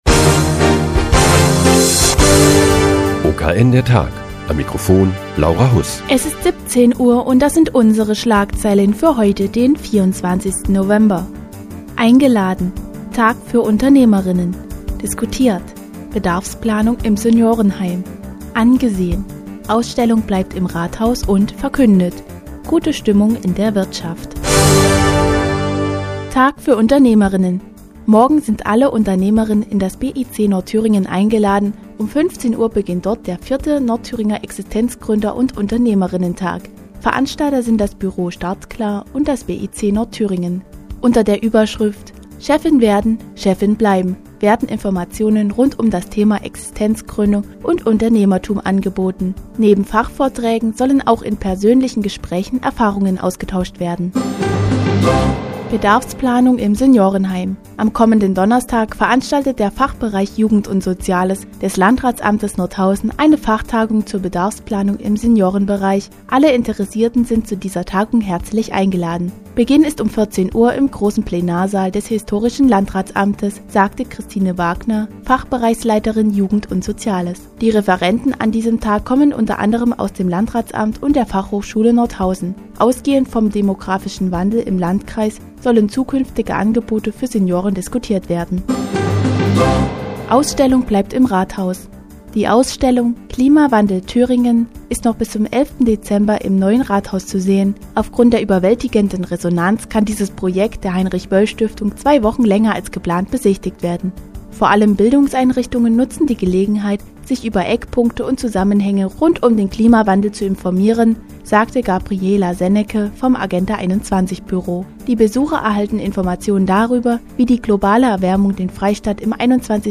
Die tägliche Nachrichtensendung des OKN ist nun auch in der nnz zu hören. Heute geht es um einen Tag für Unternehmerinnen und die Bedarfsplanung im Seniorenbereich.